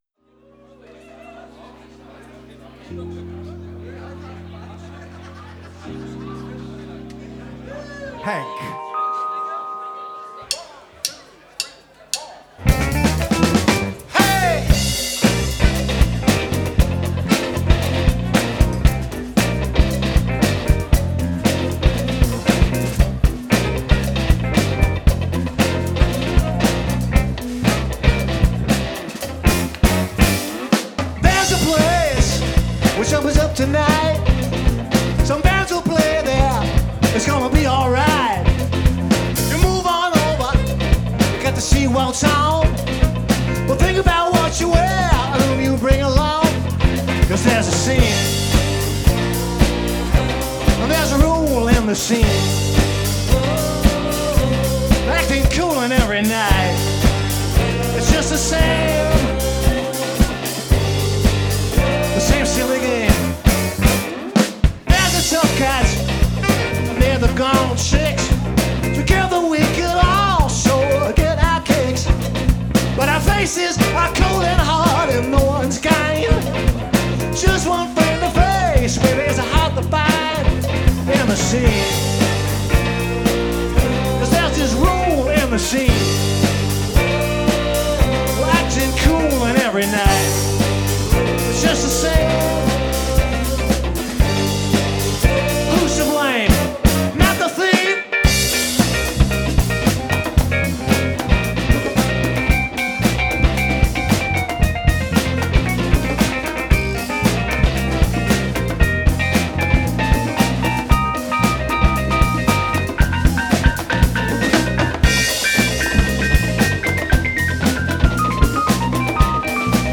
Rock `n´ Roll & Seemannsgarn/ Free Music & Tall Tales